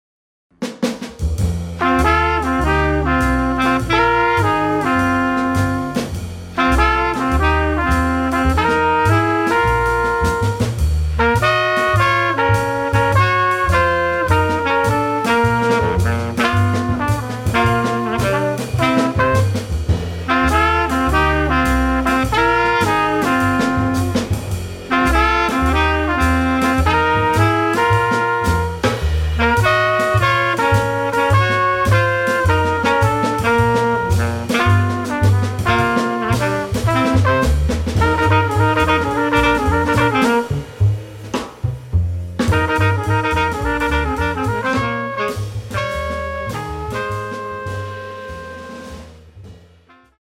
trumpet
sax
bass
drums